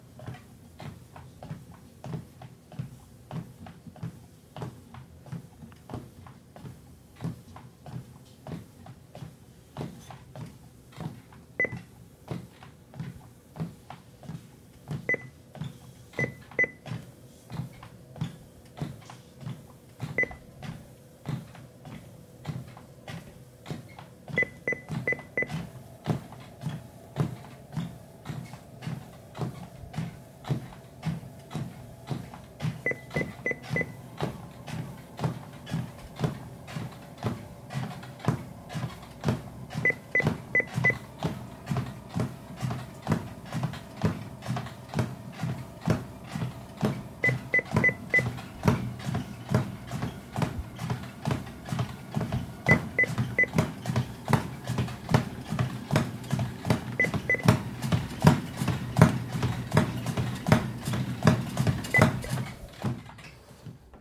Звуки беговой дорожки
Звук шагов человека на беговой дорожке без постороннего шума